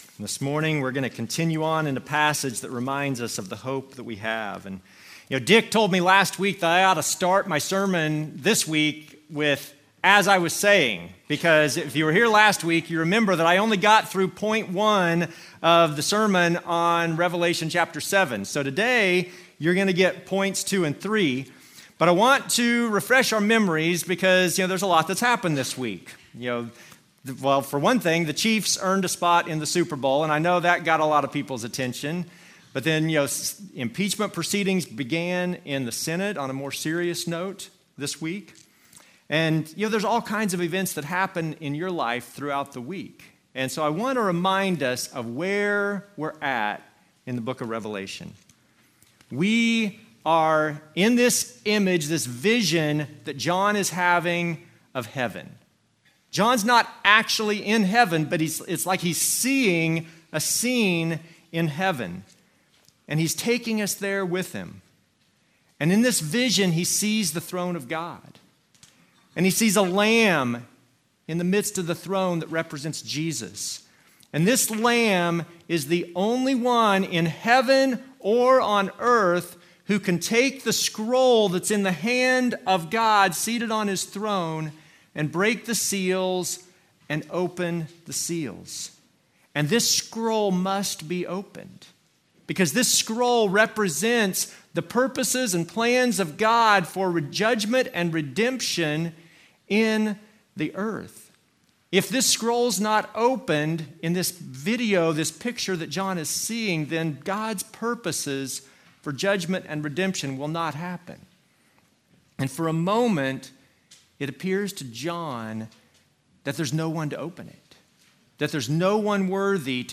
Passage: Revelation 7 Service Type: Normal service